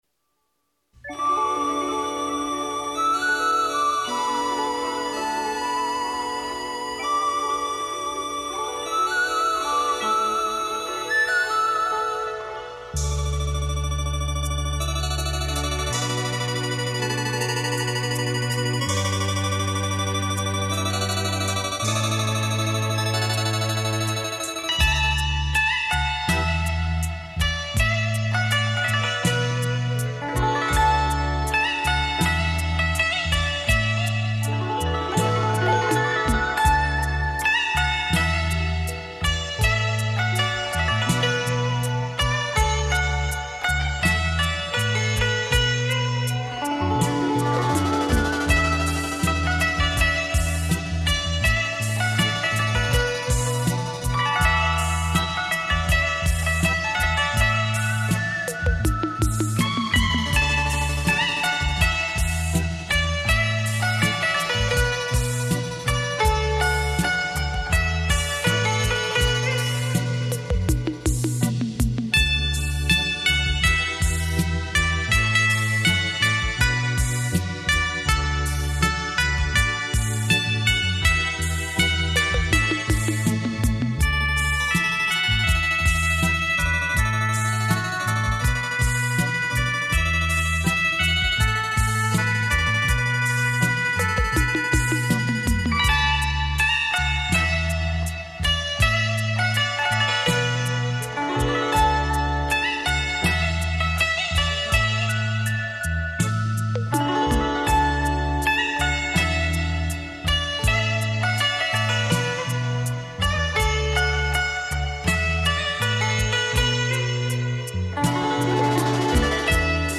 还原真实音质、别具空灵感以及临场感、是音频CD类首选载体！